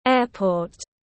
Airport /ˈeə.pɔːt/